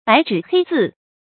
白紙黑字 注音： ㄅㄞˊ ㄓㄧˇ ㄏㄟ ㄗㄧˋ 讀音讀法： 意思解釋： 白紙上寫著黑字。